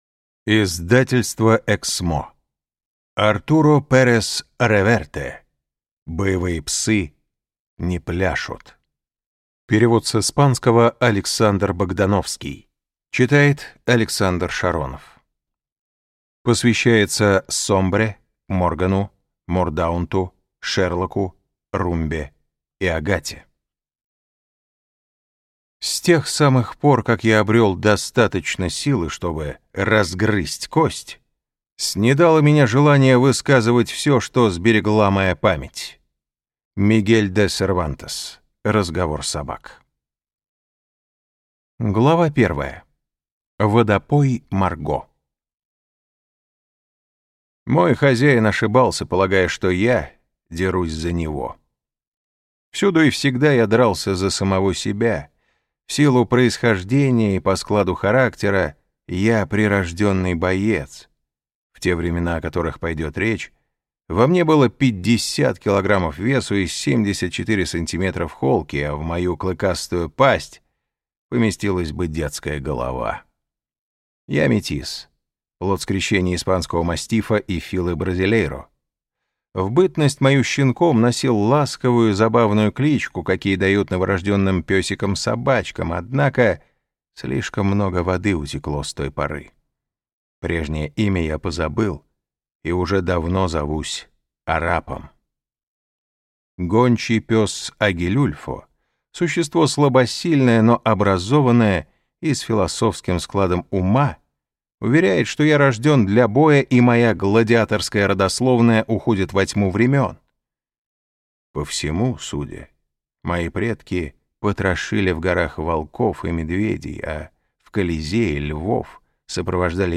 Аудиокнига Боевые псы не пляшут - купить, скачать и слушать онлайн | КнигоПоиск